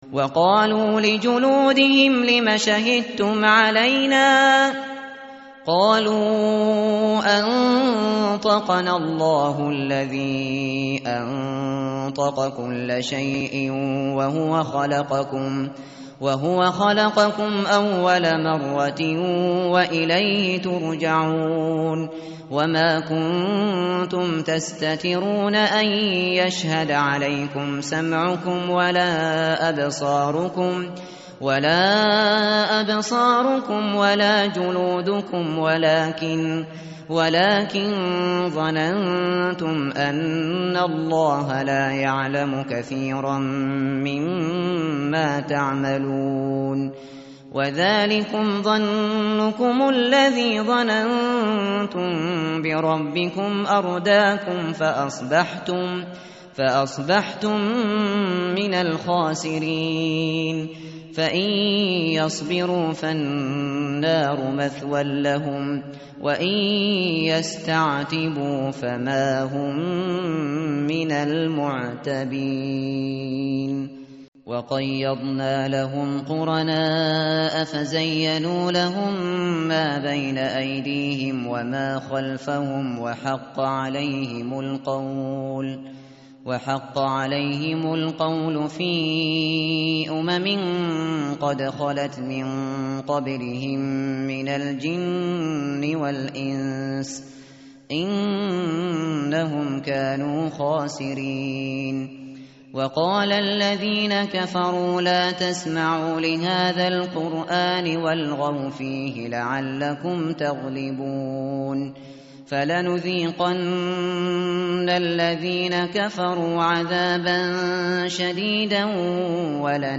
متن قرآن همراه باتلاوت قرآن و ترجمه
tartil_shateri_page_479.mp3